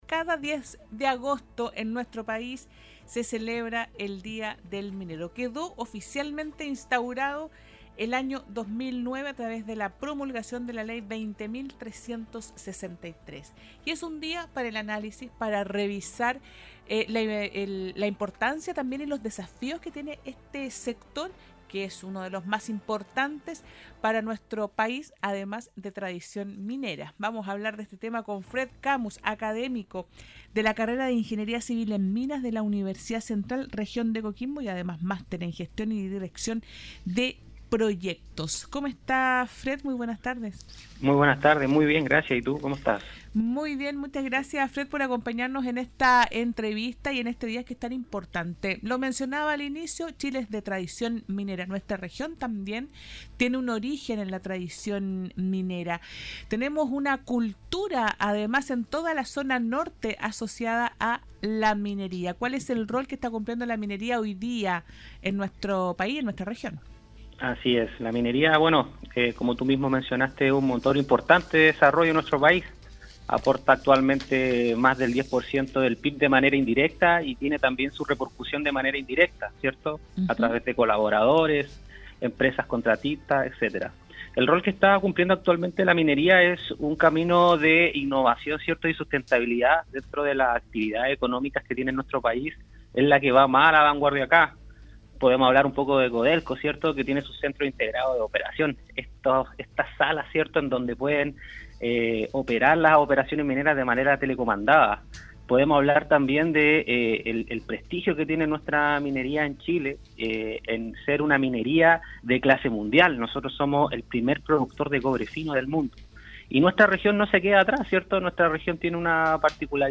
Litio, Cobalto, mitigación del impacto ambiental y reutilización de aguas, fueron los temas desarrollados durante la entrevista que puedes escuchar a continuación.